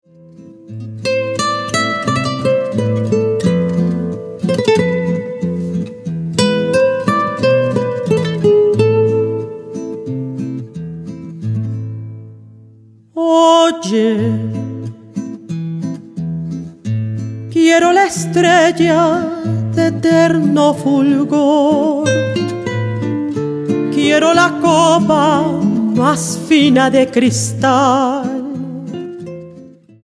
mezzosoprano
guitarras